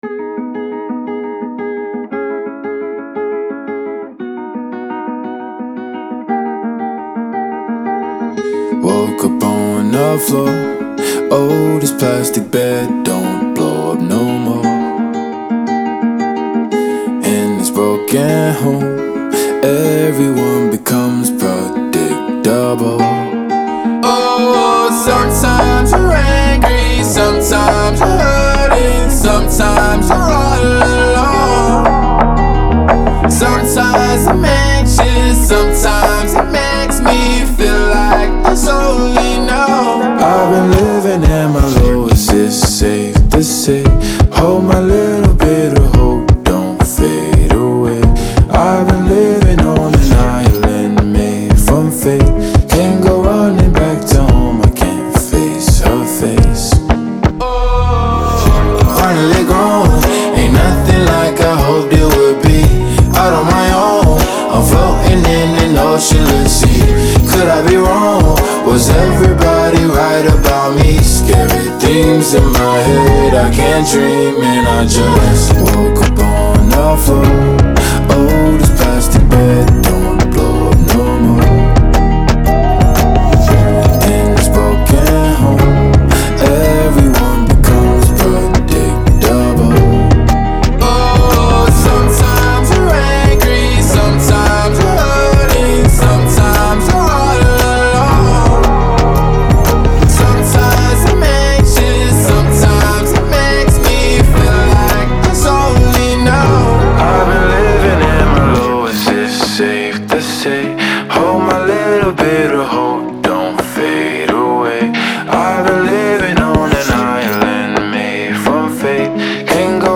Genre : Hip-Hop